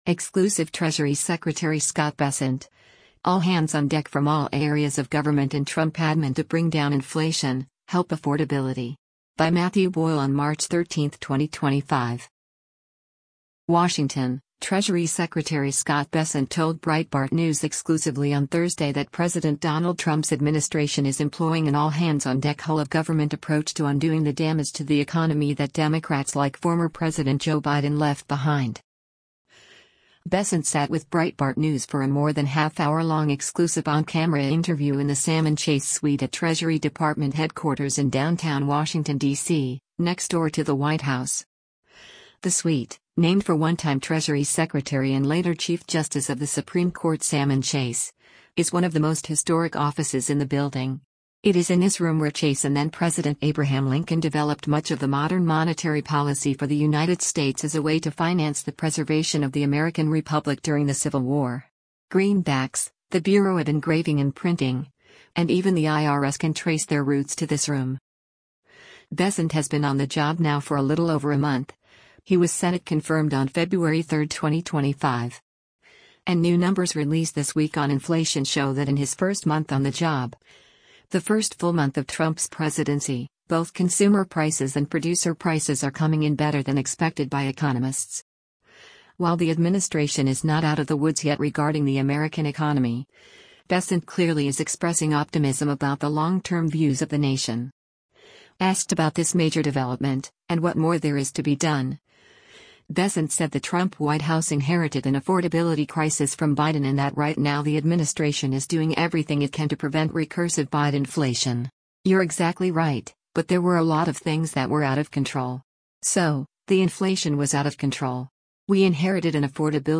Bessent sat with Breitbart News for a more-than-half-hour-long exclusive on-camera interview in the Salmon Chase Suite at Treasury Department headquarters in downtown Washington, DC, next door to the White House.